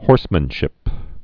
(hôrsmən-shĭp)